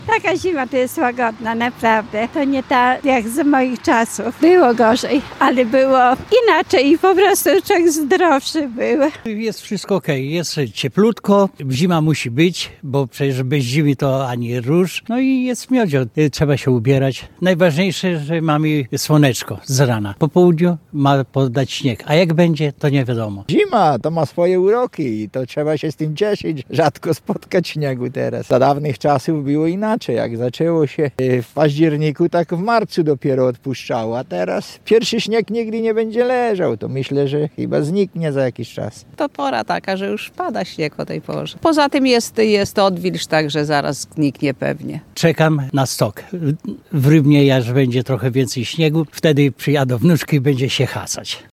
Sonda: Mieszkańcy Łomży po ataku śniegu. „Zima ma swoje uroki”
Skoro zbliża się kalendarzowa zima, to musi być śnieg – mówili nam mieszkańcy Łomży, których zapytaliśmy o wrażenia po tym, jak ulice miasta stały się białe.